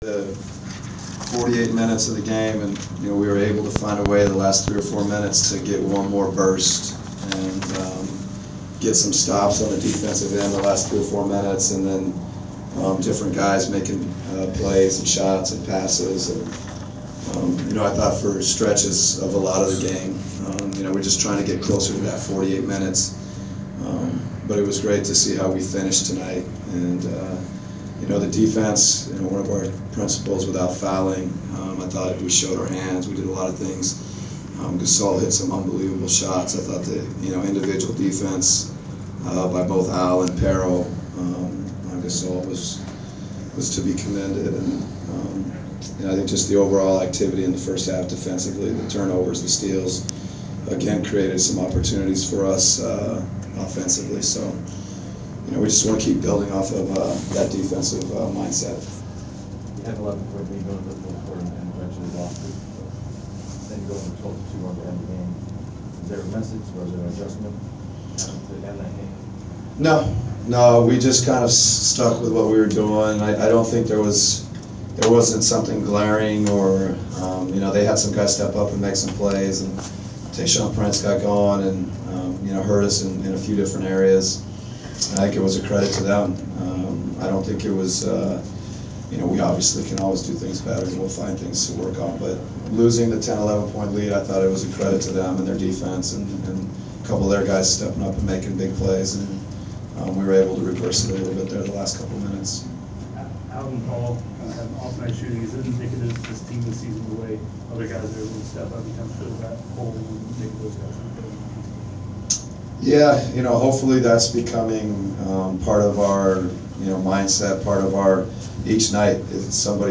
Inside the Inquirer: Postgame presser with Atlanta Hawks’ head coach Mike Budenholzer (1/7/15)
We attended the postgame presser of Atlanta Hawks’ head coach Mike Budenholzer following his team’s 96-86 home victory over the Memphis Grizzlies on Jan. 7. Topics included defending Marc Gasol, the play of the Hawks’ bench, Al HorfordJeff Teague and the home court advantage in Atlanta.